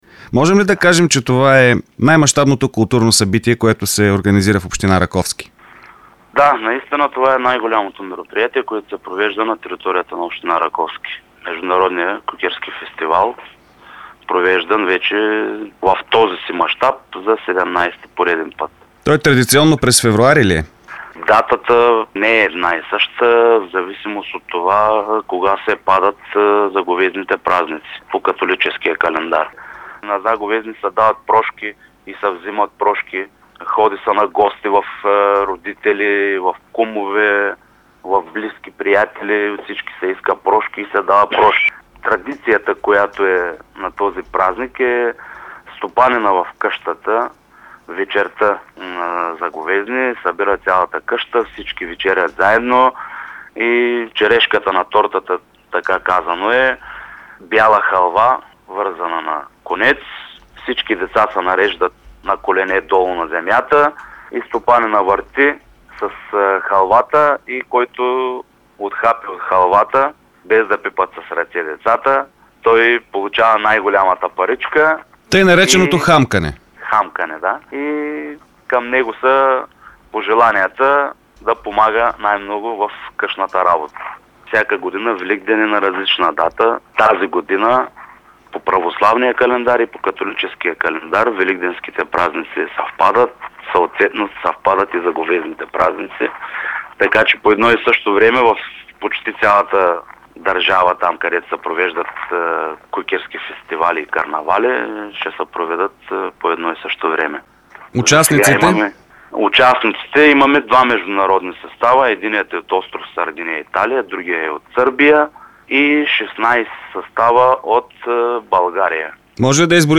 17-тото издание на Международния фестивал за кукерски и маскарадни игри „Кукове“ се открива довечера в Раковски. Тази година има два състава от чужбина – единият е от о. Сардиния (Италия), а другият е от Сърбия, анонсира в ефира на Дарик радио зам.-кметът на община Раковски Божидар Стрехин.
Чуйте цялото интервю с Божидар Стрехин от аудиото